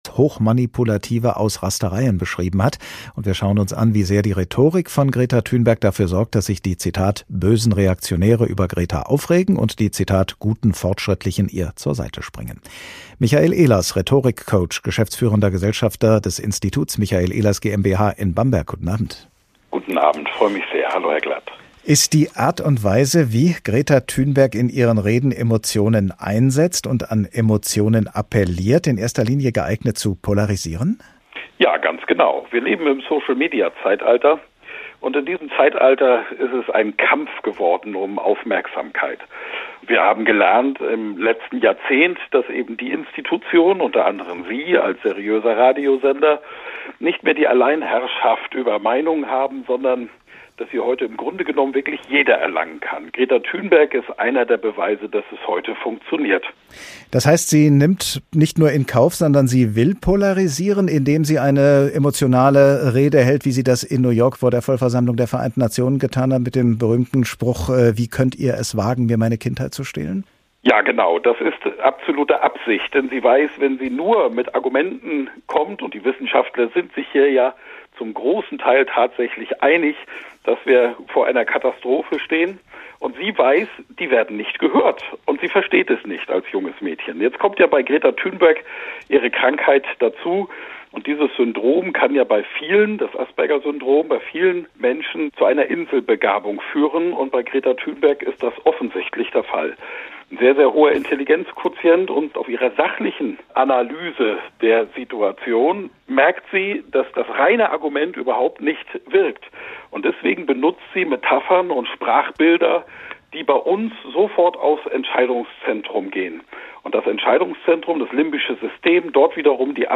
hr2-kultur Der Tag Interview